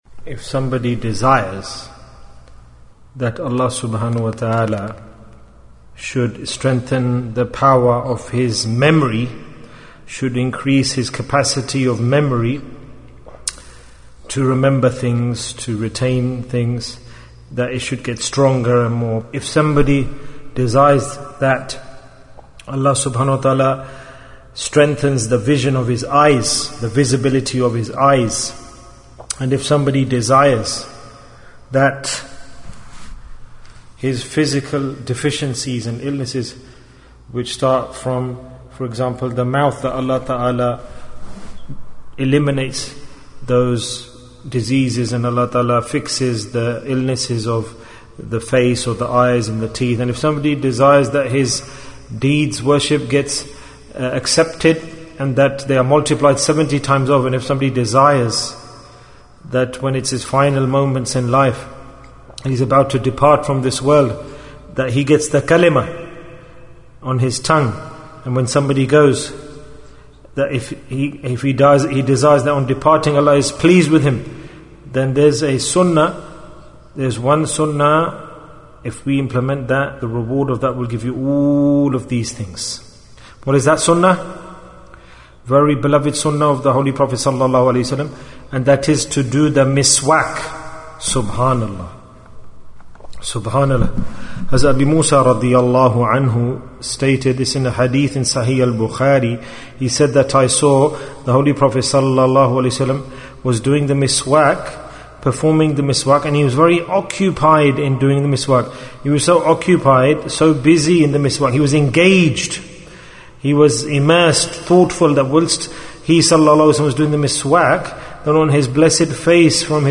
Benefits of Miswak Bayan, 13 minutes22nd April, 2019